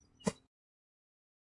玩家 "草丛中的脚步声 "硬4
描述：用Zoom Recorder录制的草地上的脚步声
Tag: 脚步 台阶 一步 脚步声 现场记录 行走